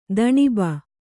♪ daṇiba